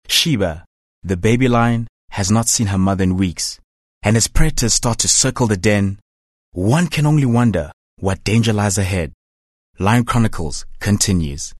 Male
South African English , American English , British English
Approachable, Authoritative, Bright, Bubbly, Character
My accent is South African but i can switch to American and British accent as well.
Microphone: Rode NT2A
Audio equipment: Vocal Isolation booth